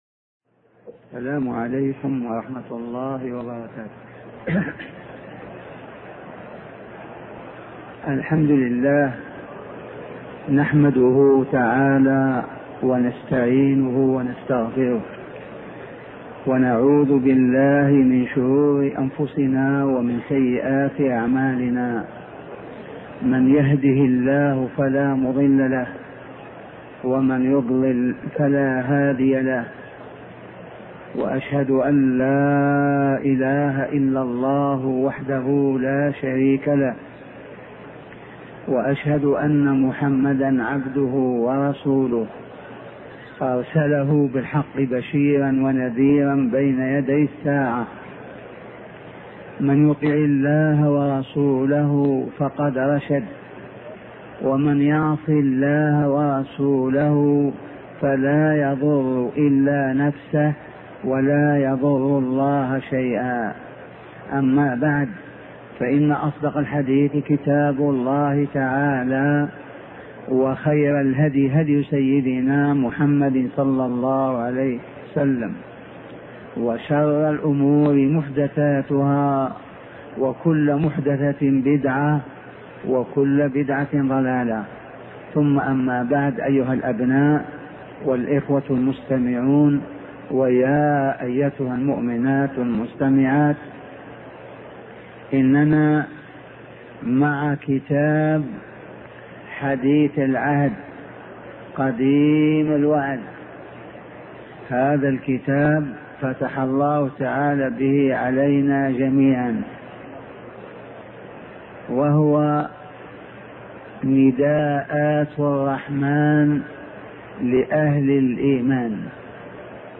شبكة المعرفة الإسلامية | الدروس | نداءات الرحمن لأهل الإيمان 001 |أبوبكر الجزائري